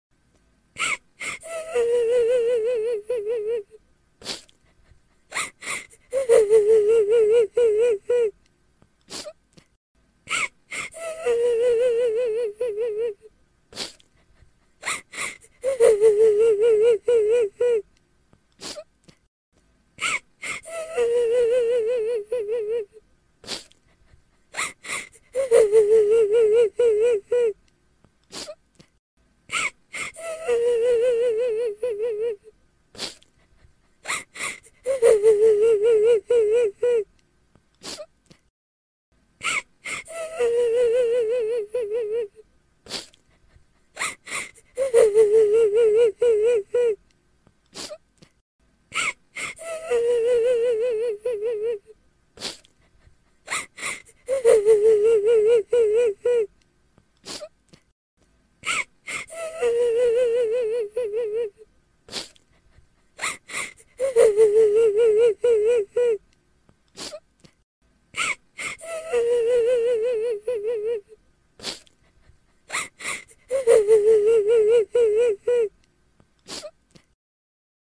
Suara Orang menangis sedih
Kategori: Suara manusia
Keterangan: Suara orang menangis menawarkan efek suara wanita menangis sedih mp3.
suara-orang-menangis-sedih-id-www_tiengdong_com.mp3